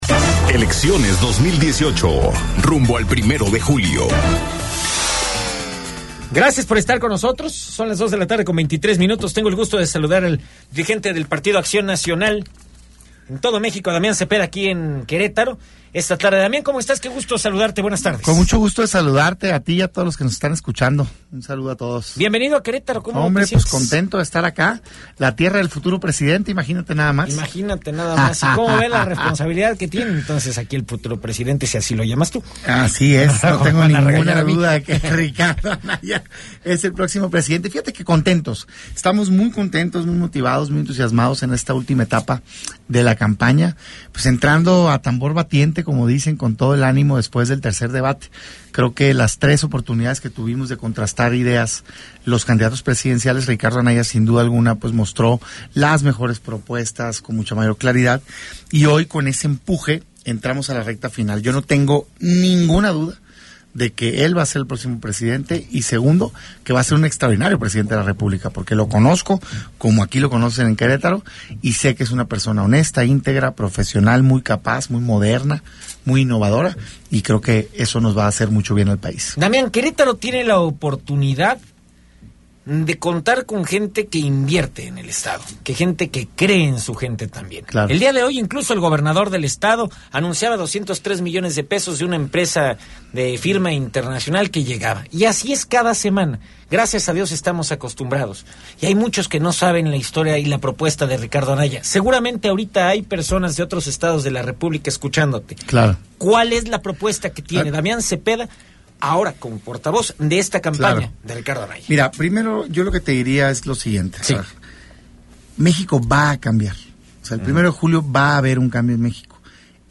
Entrevista en cabina con Damián Zepeda presidente nacional del PAN - RR Noticias